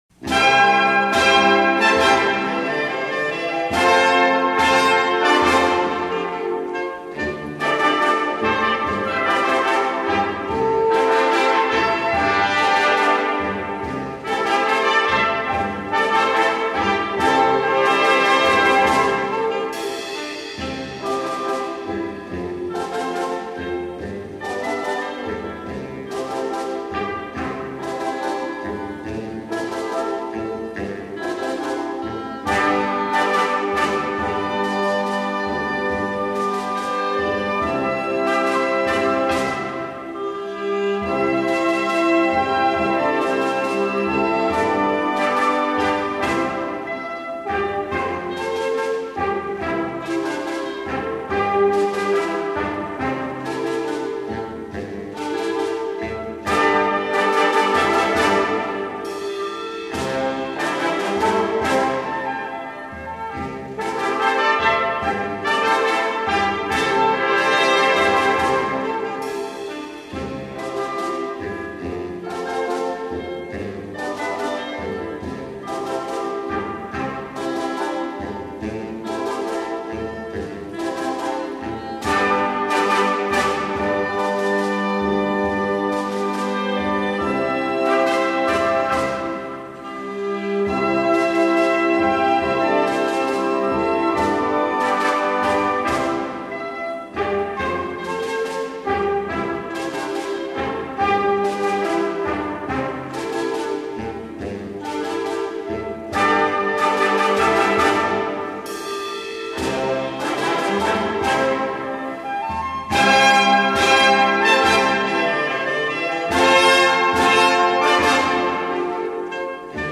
編曲　県警音楽隊
それで、中高年世代の方々に一番親しみやすい音楽と考えたとき、歌い易い歌詞、覚えやすく馴染みやすいメロディー、そして、１日でも永く歌い続けていただける心に残る歌と言えば、演歌ではないかと考えたわけです。